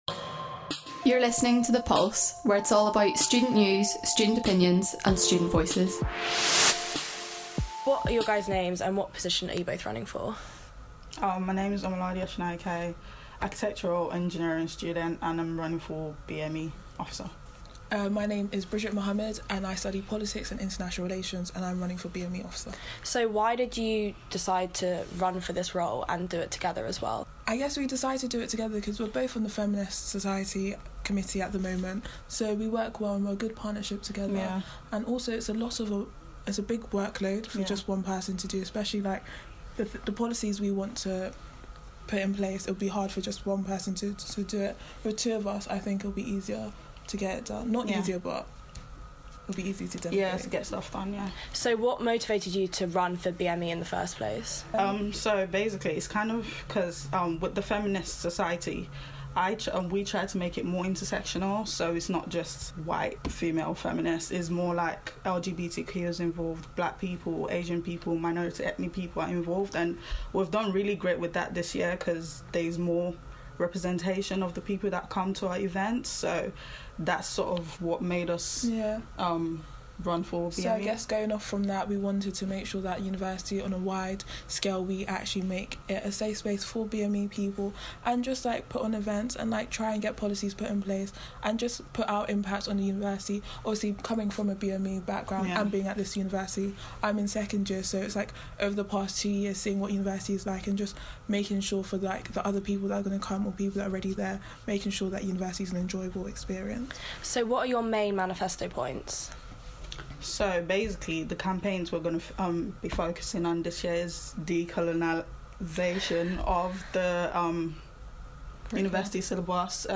We interviewed this year's SU BME Officer Candidates.